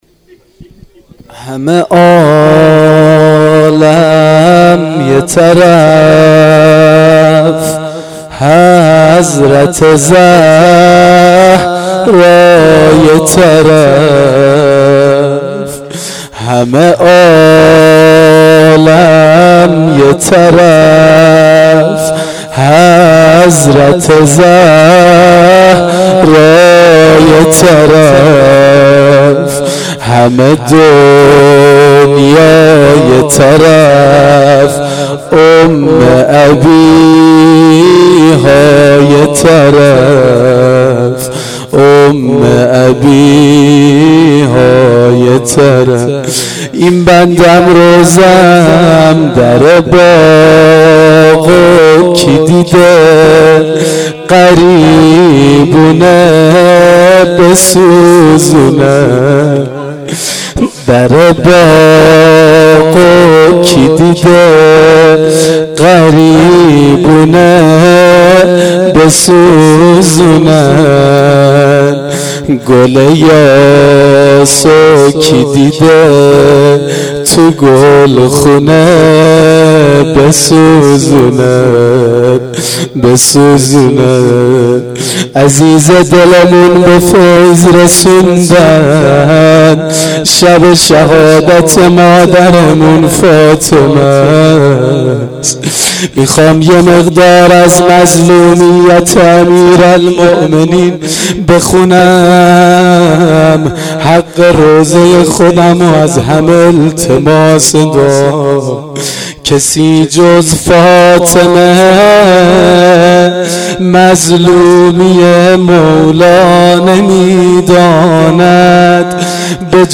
روضه شب دوم فاطمیه دوم